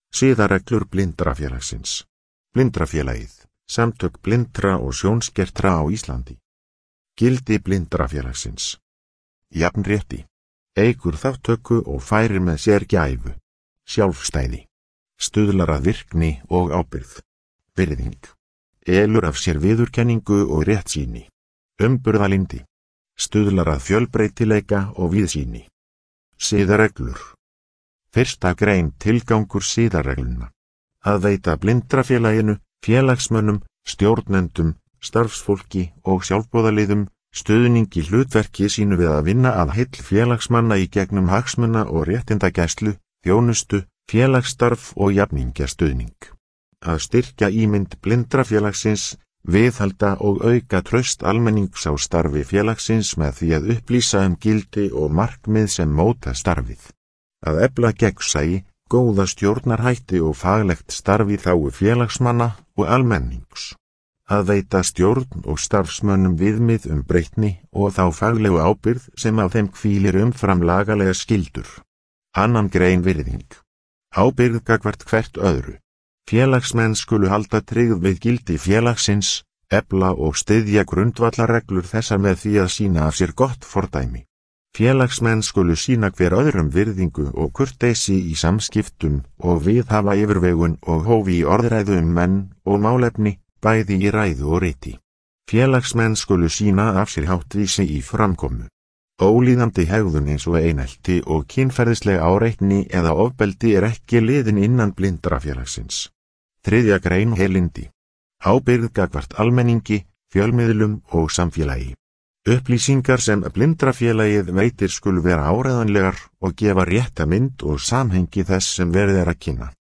Upplesið